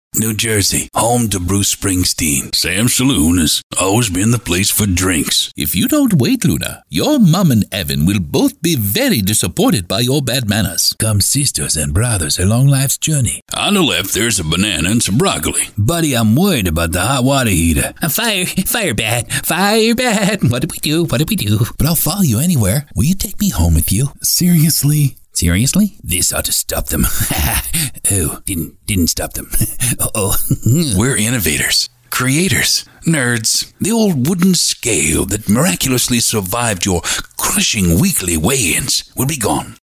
Voice Over Actor
Characters Voice Over